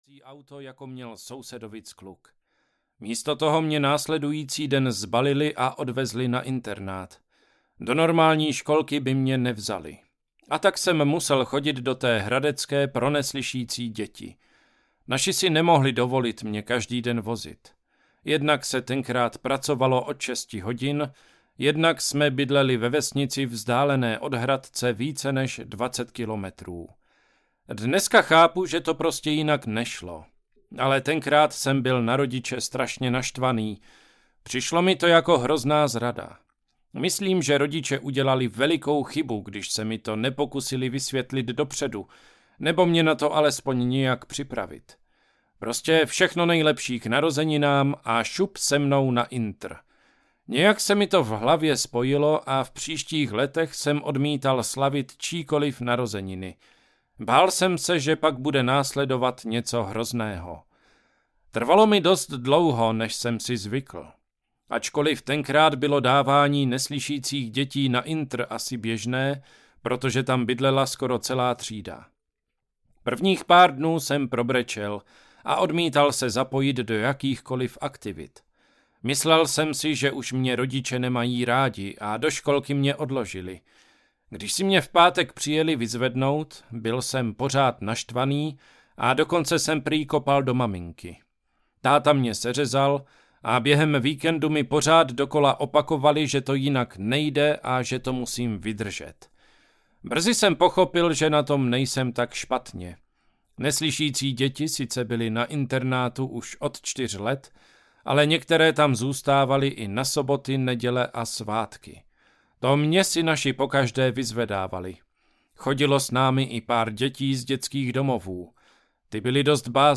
Hluchavky audiokniha
Ukázka z knihy